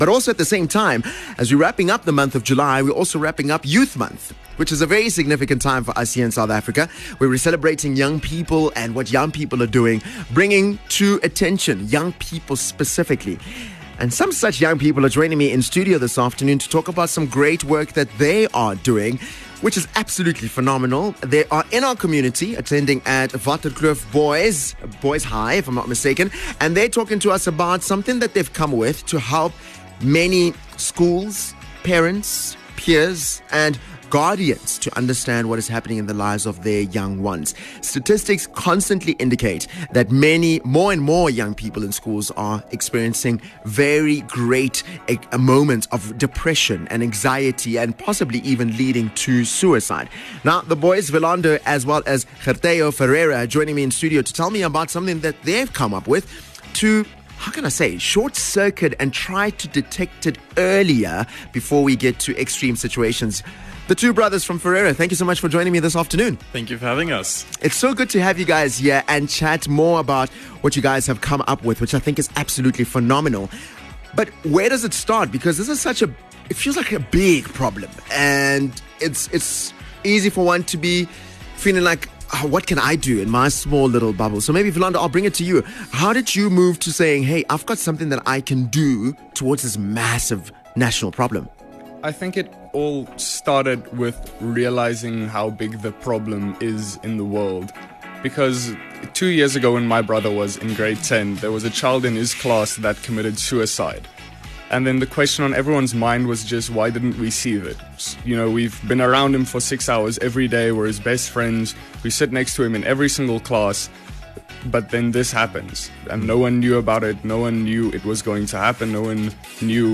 Interview on Impact 103